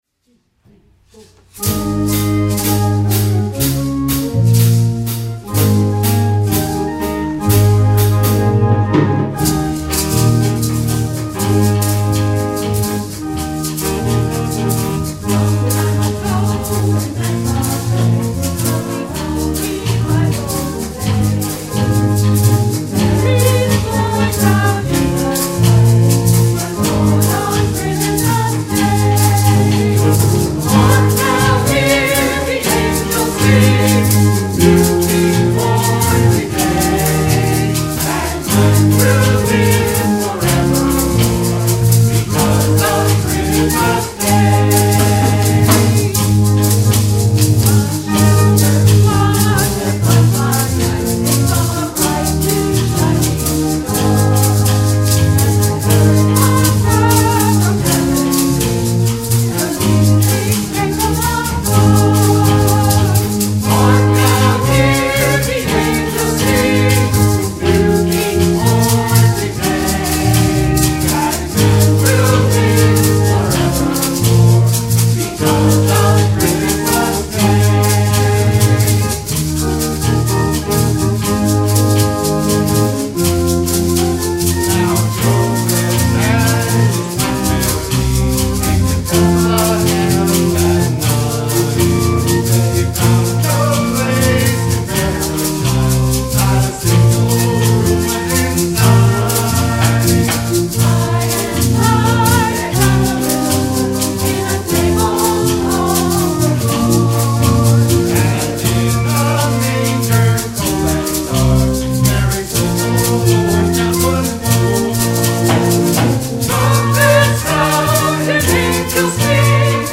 Algiers UMC Choir